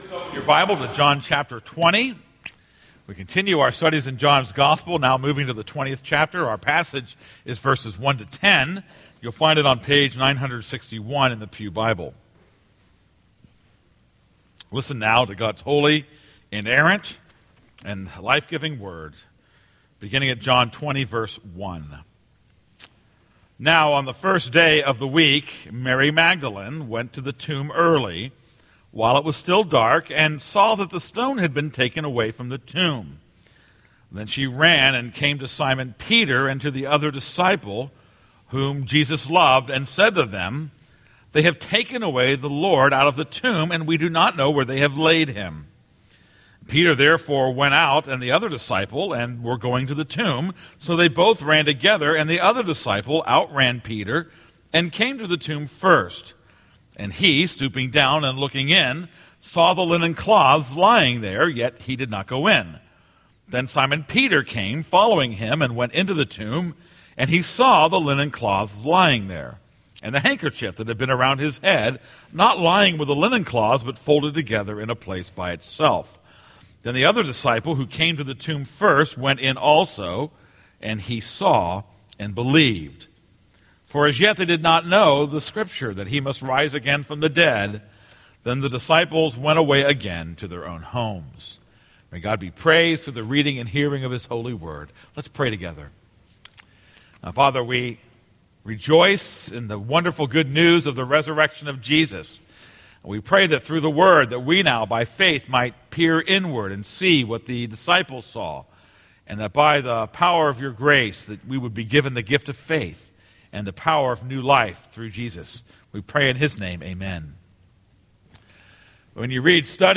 This is a sermon on John 20:1-10.